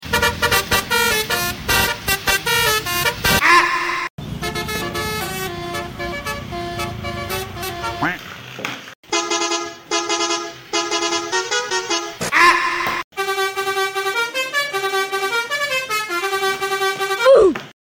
Mainan Kereta api dan mobil mobilan sound effects free download
Sound Effect Mainan Kereta api dan mobil-mobilan Anak